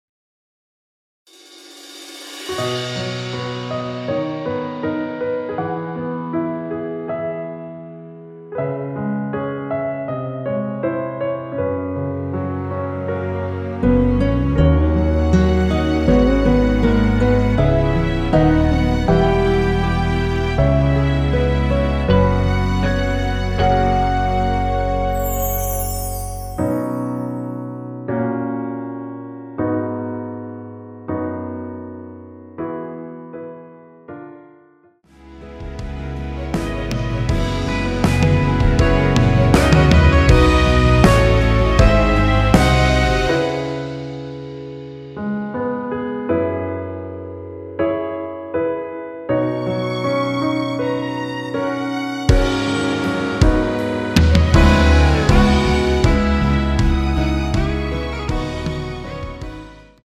원키에서(-2)내린 (1절+후렴) MR입니다.
앞부분30초, 뒷부분30초씩 편집해서 올려 드리고 있습니다.
중간에 음이 끈어지고 다시 나오는 이유는